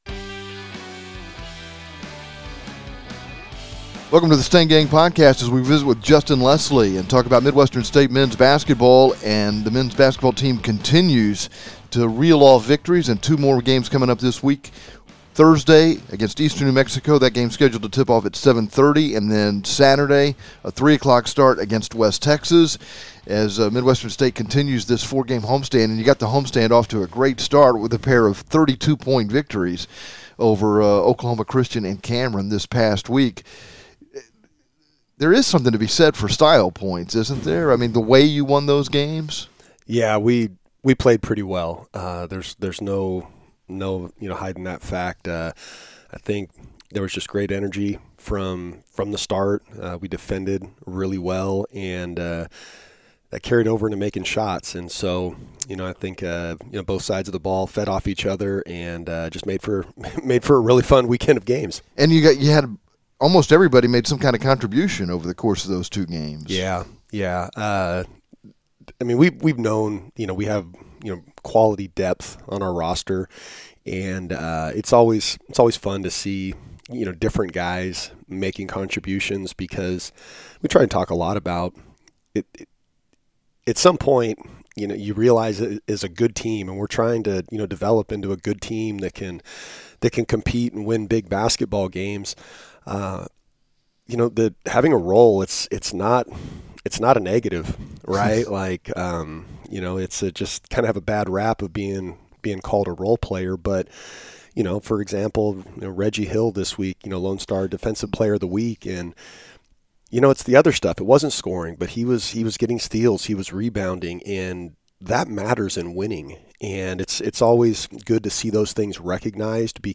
A candid conversation